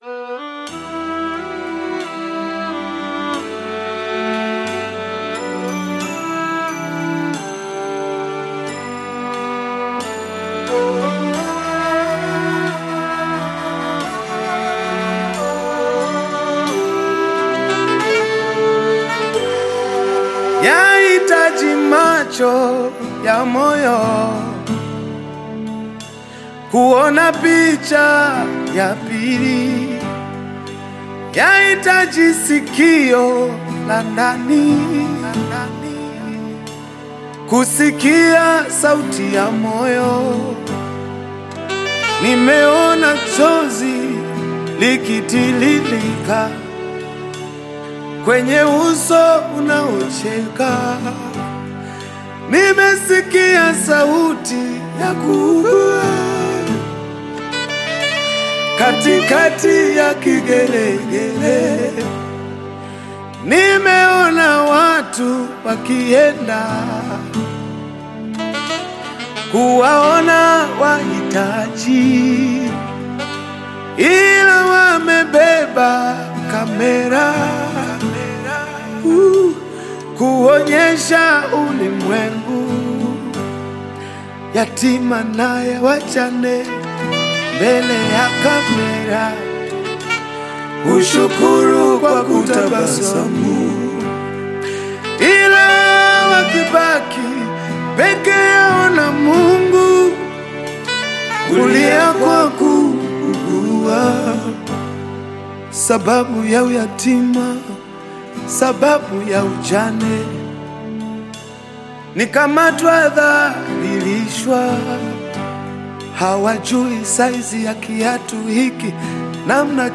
Tanzanian Gospel singer and songwriter
a gospel and motivational song
Nyimbo za Dini